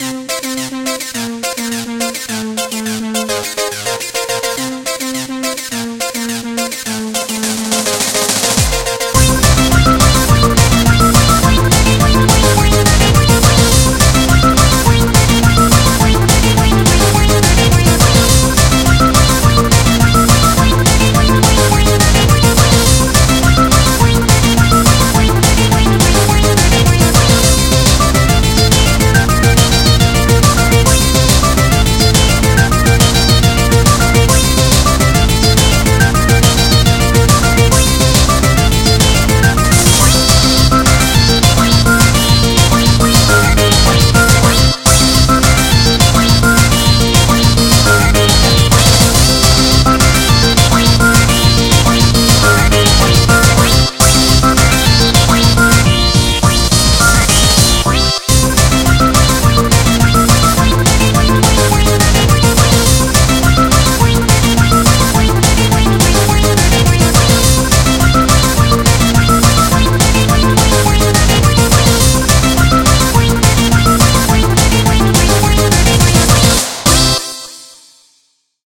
BPM210
Audio QualityPerfect (High Quality)